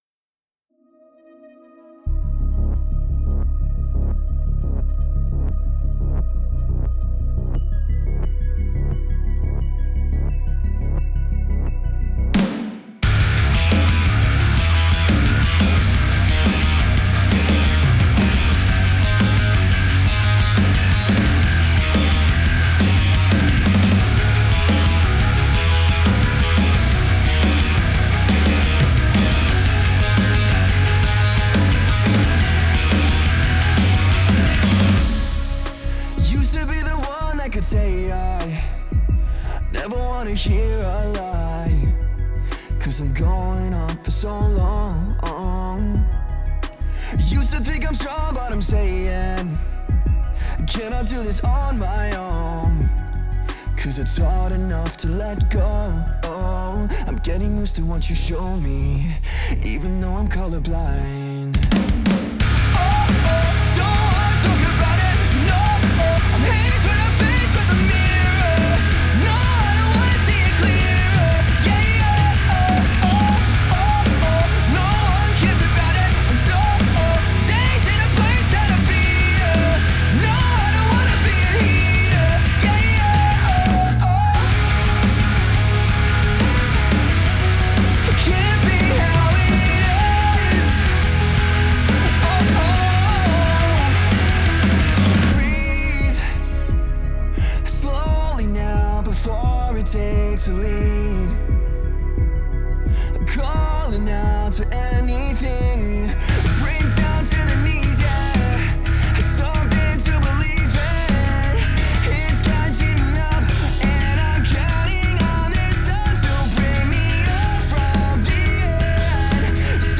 Reamping, Mixing, Mastering